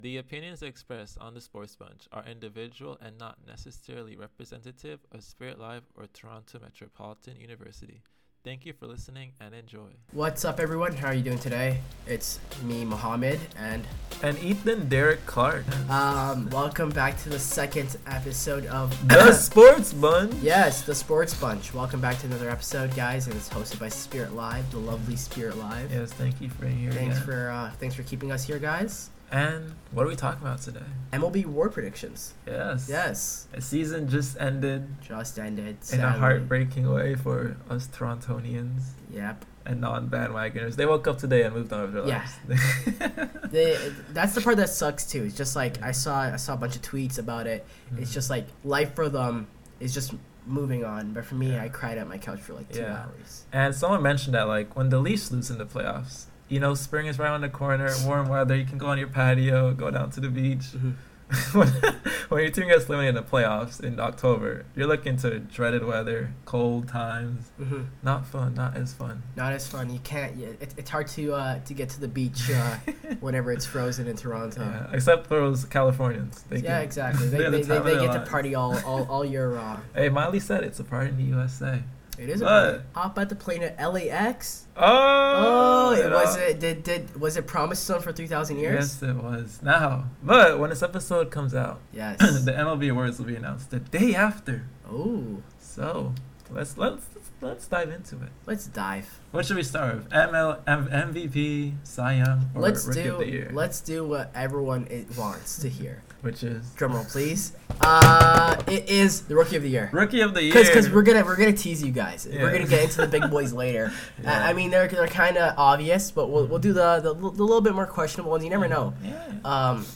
Two friends. One mic.
The SportsBunch is where sports talk gets loud, unfiltered, and fun.